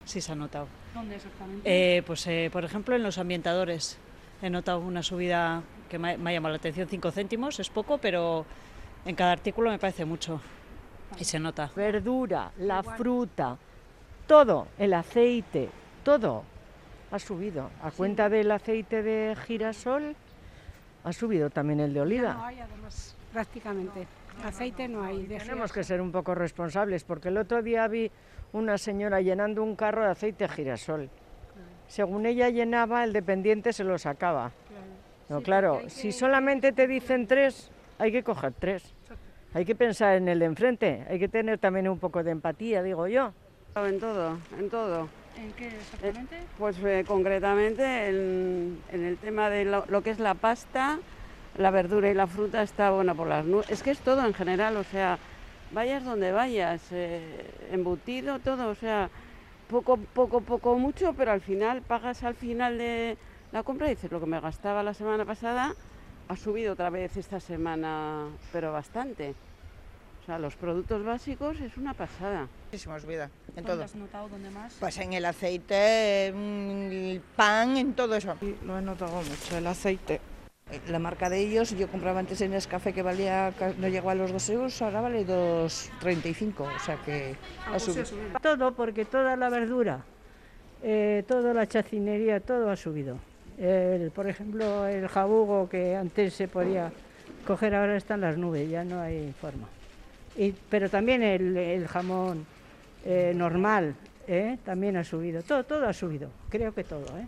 Onda Vasca ha salido a la calle a conocer si el aumento de los precios en los productos afecta en los bolsillos